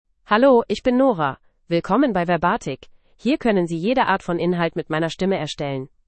FemaleGerman (Germany)
NoraFemale German AI voice
Voice sample
Listen to Nora's female German voice.
Nora delivers clear pronunciation with authentic Germany German intonation, making your content sound professionally produced.